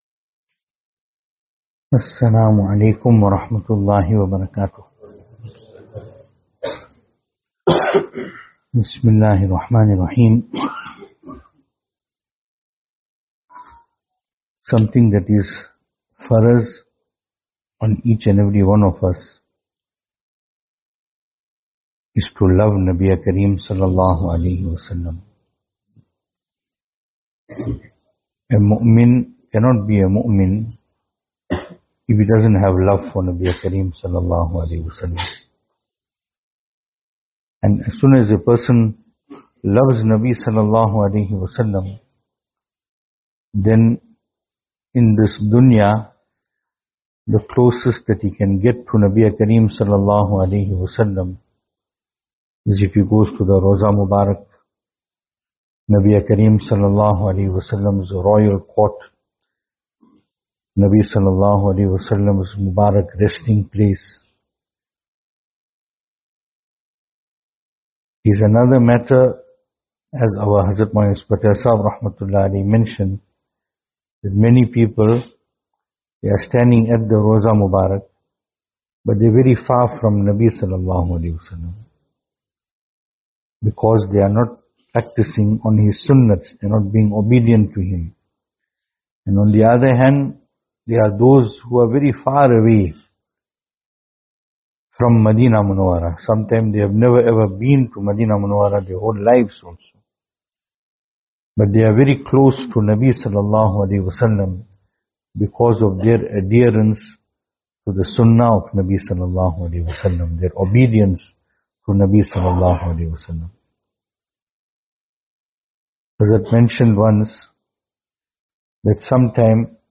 Venue: Albert Falls , Madressa Isha'atul Haq Series: Islahi Majlis Service Type: Islahi Majlis Topics: Islahi Majlis « Advices for those leaving for Umrah, Part 2.